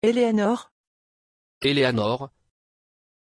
Aussprache von Éleanor
pronunciation-éleanor-fr.mp3